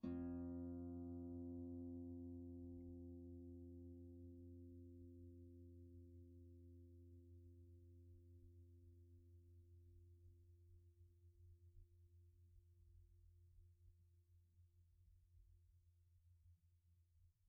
KSHarp_F2_mf.wav